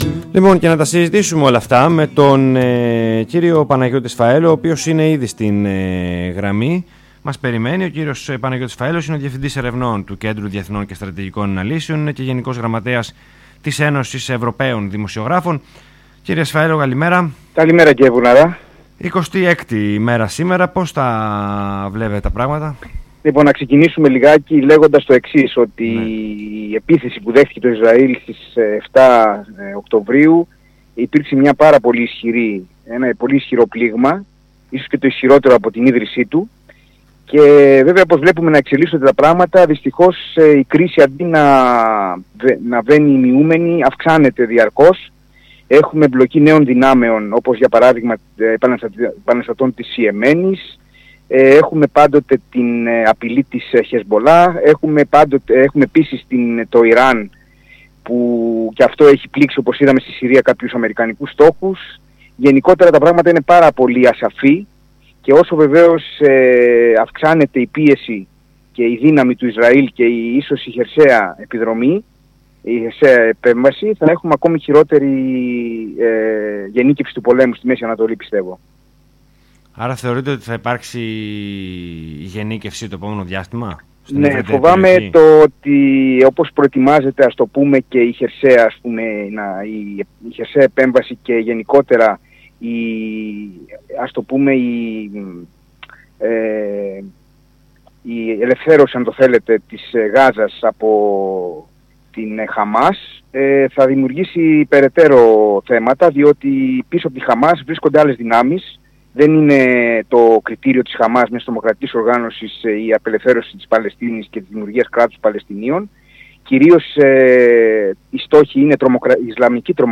ΣΥΝΕΝΤΕΥΞΗ ΔΡ.